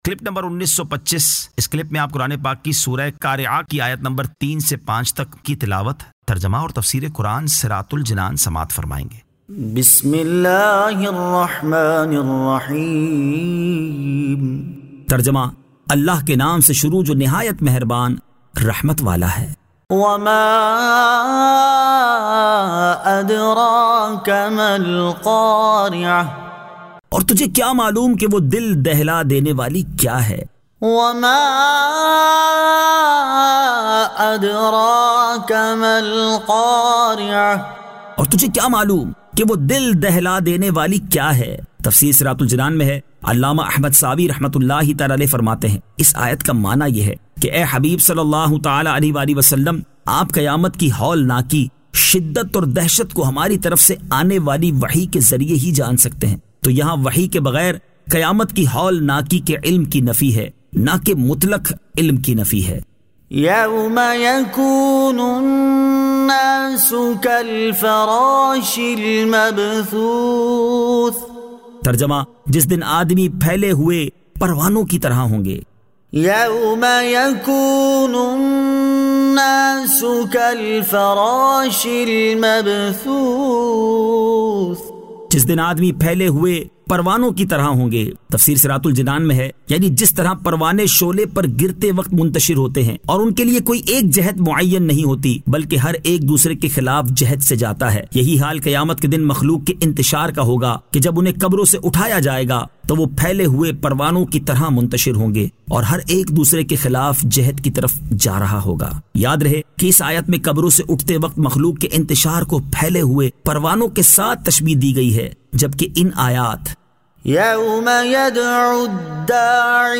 Surah Al-Qari'ah 03 To 05 Tilawat , Tarjama , Tafseer